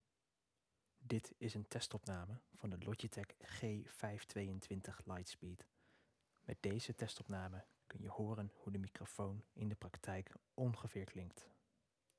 Opnamekwaliteit
De microfoon klinkt namelijk érg goed, zelfs in rumoerige omstandigheden.
Logitech G522 Lightspeed (2025): 2.4Ghz modus (± 160 euro)
G522-Opname.m4a